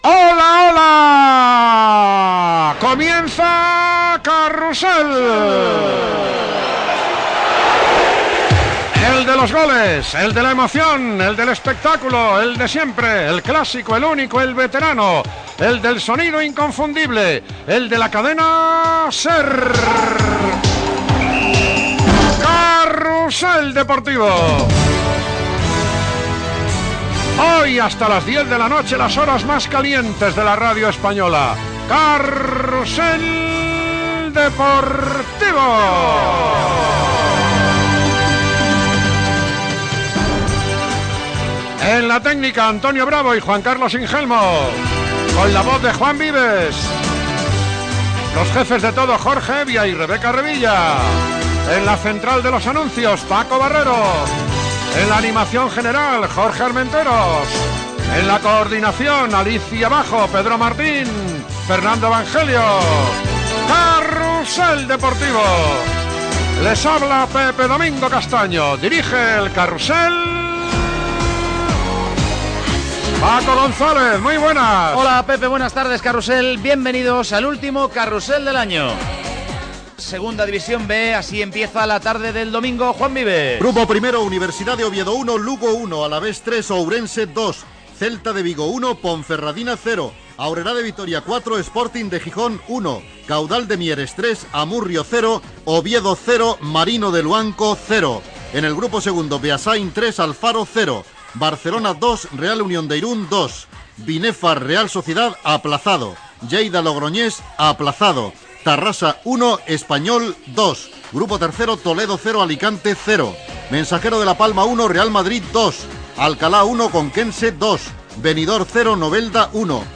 Sintonia, presentació amb els noms de l'equip, resultats de la Segona Divisió B de futbol masculí.
Esportiu